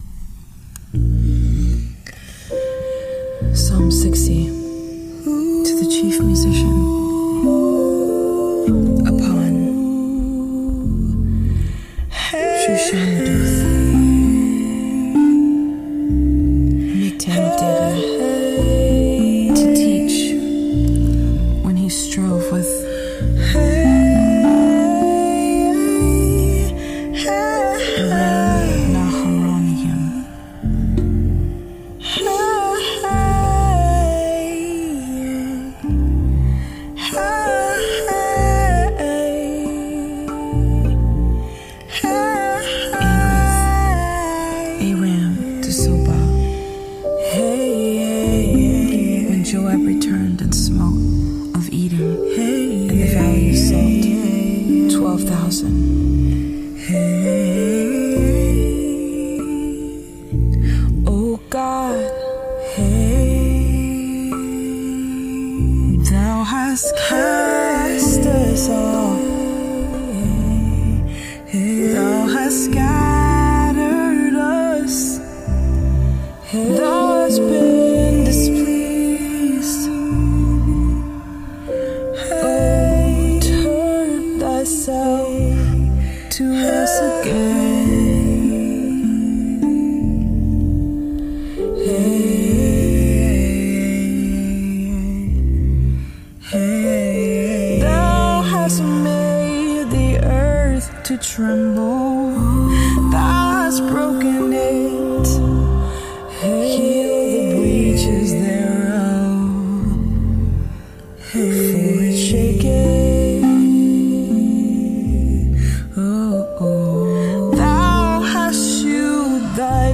Psalms 60 Rav Vast & Beats Sessions